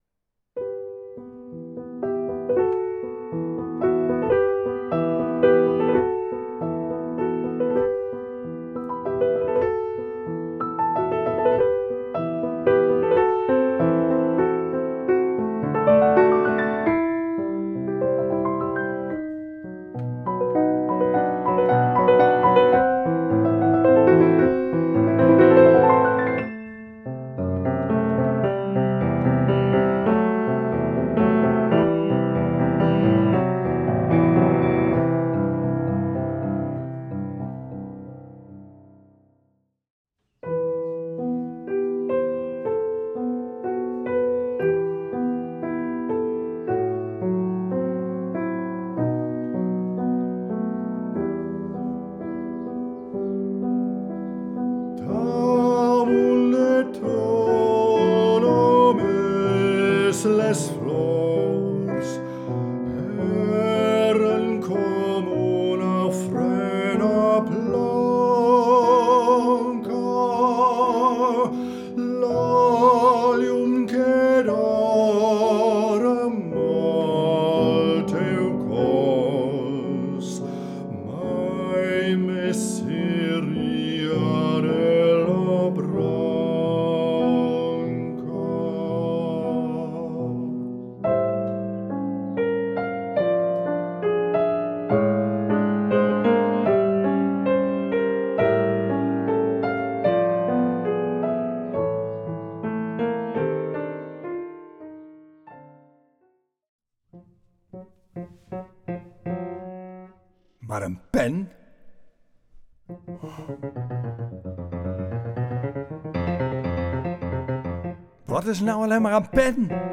Duo TuimelKruid presenteert De Man die Don Quichot werd – een poëtisch theaterconcert waarin muziek en verhaal samensmelten tot een evocatieve reis door de verbeelding van schrijver Miguel de Cervantes en zijn legendarische dolende ridder Don Quichot.
Sublieme liederen en meesterlijke pianowerken van onder anderen Ravel, Ibert, Mompou, De Falla en Debussy vormen de muzikale kern van deze voorstelling. Afgewisseld met korte monologen en dialogen ontstaat een doorlopend, filmisch geheel – een theatrale beleving die ontroert, prikkelt en betovert.